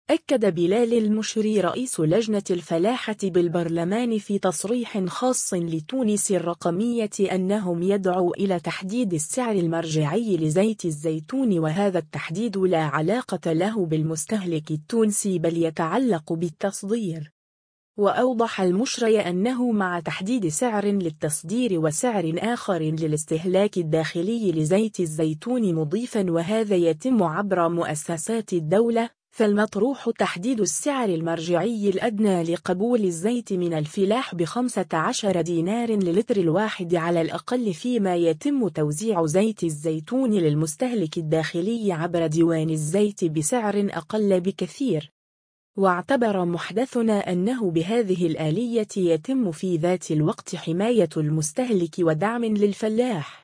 أكد بلال المشري رئيس لجنة الفلاحة بالبرلمان في تصريح خاص لـ «تونس الرقمية” أنهم يدعو الى تحديد السعر المرجعي لزيت الزيتون وهذا التحديد لا علاقة له بالمستهلك التونسي بل يتعلق بالتصدير.